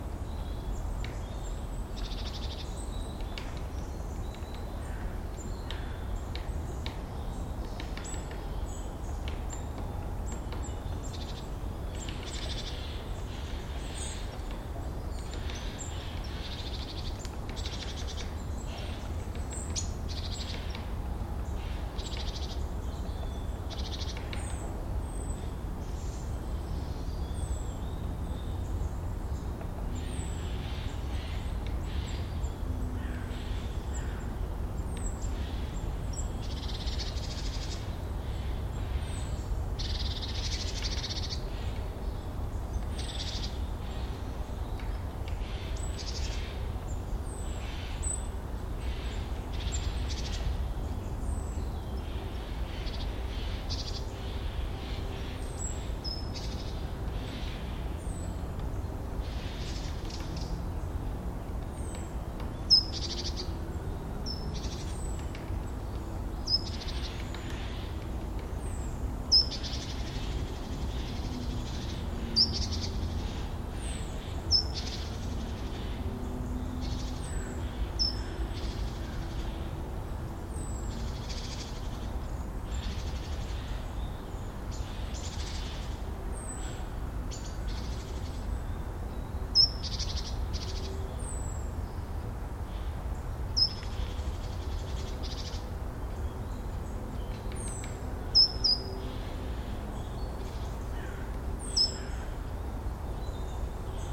Ambience.mp3